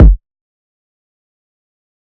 Kick (Charged Up).wav